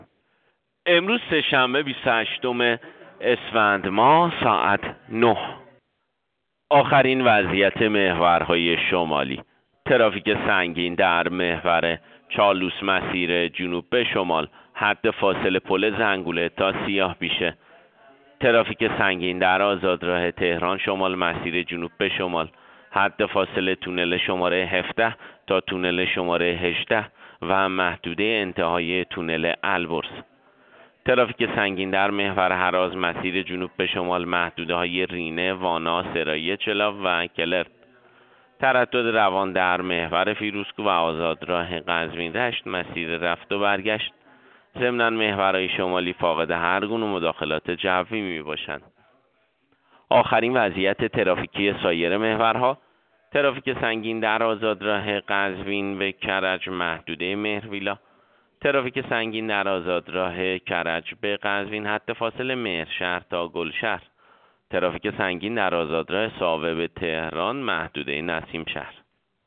گزارش رادیو اینترنتی از آخرین وضعیت ترافیکی جاده‌ها ساعت ۹ بیست و هشتم اسفند؛